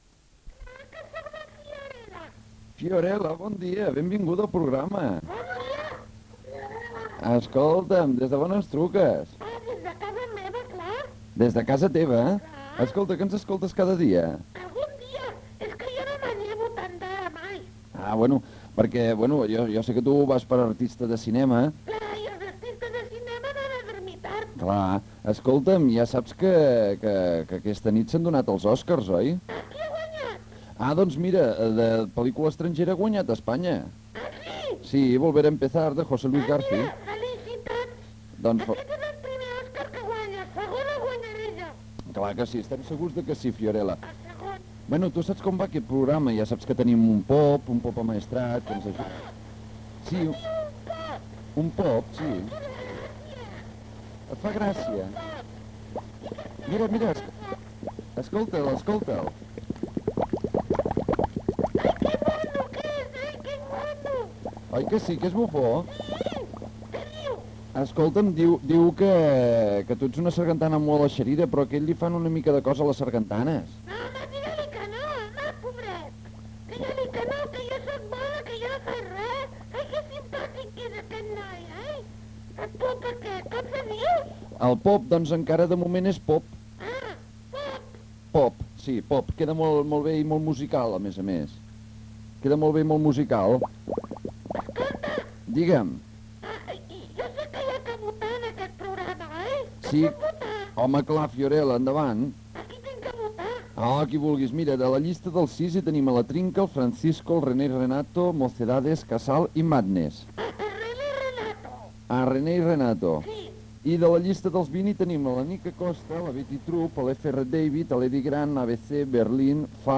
Gènere radiofònic Participació
Banda FM